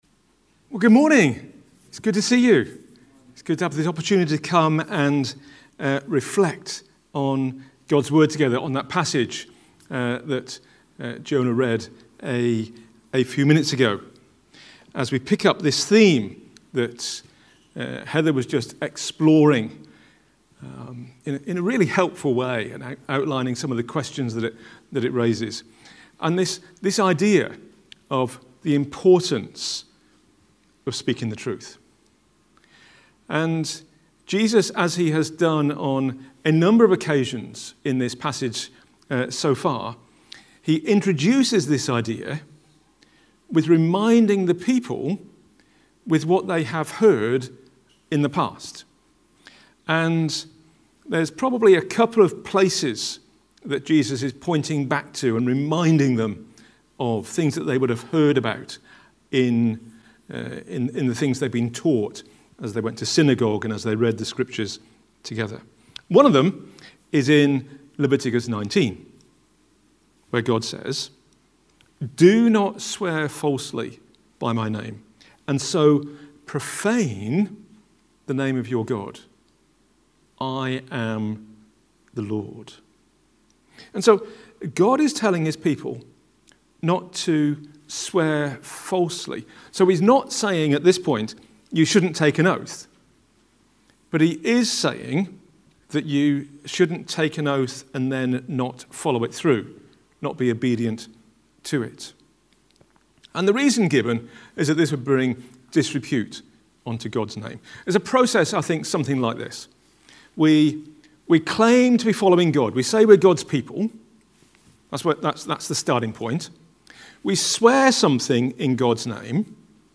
A talk from the series "The Sermon on the Mount."